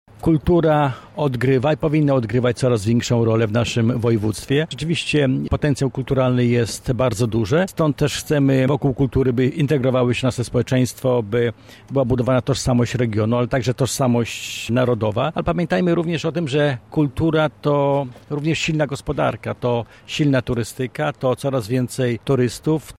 Przez kulturę do serca Polaków. Przez dwa dni eksperci będą dyskutować, jak to zrobić – mówi Krzysztof Grabczuk, wicemarszałek województwa.